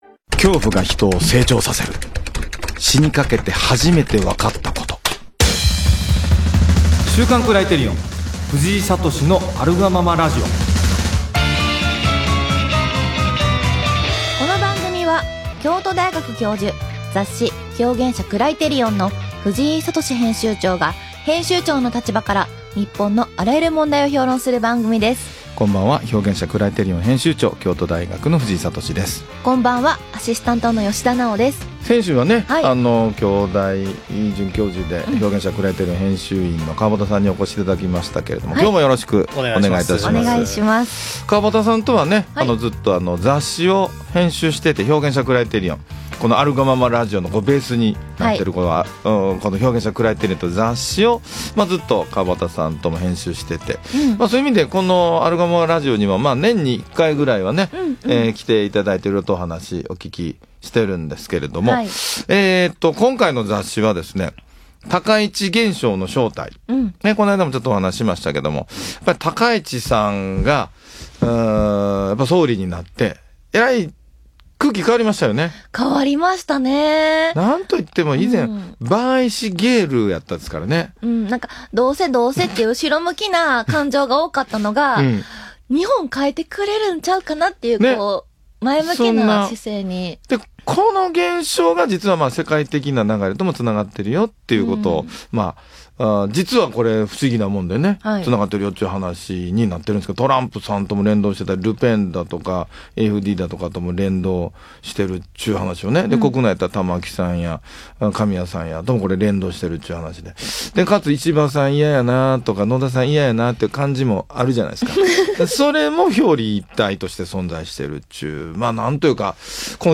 【ラジオ】「恐怖」が人を成長させる――死にかけて初めて分かったこと